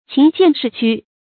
情見勢屈 注音： ㄑㄧㄥˊ ㄒㄧㄢˋ ㄕㄧˋ ㄑㄩ 讀音讀法： 意思解釋： 情：真情；見：通「現」，暴露；勢：形勢；屈：屈曲。